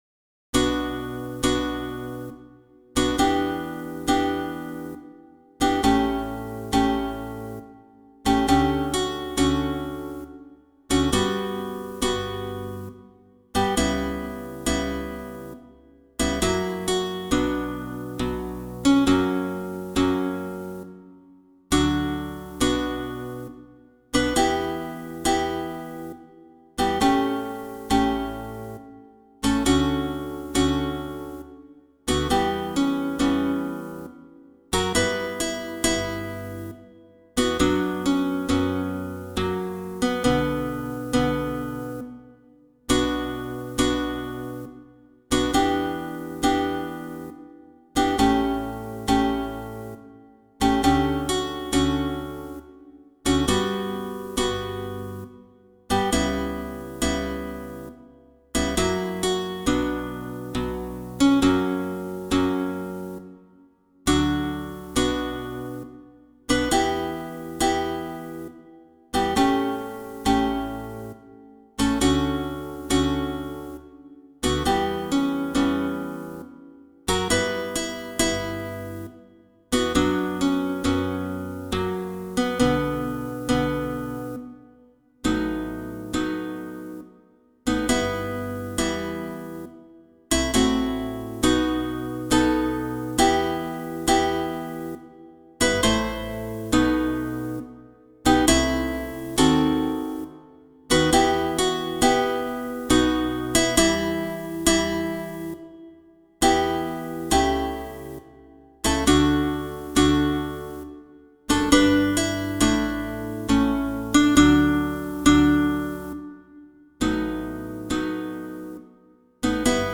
Arrangement für 4 Gitarren (Bass ad lib.)